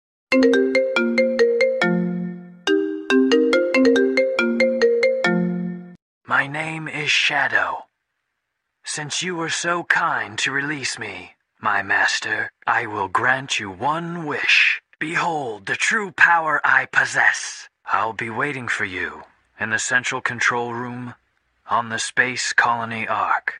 📌 Disclaimer: This is a fun fake call and not affiliated with any official character or franchise.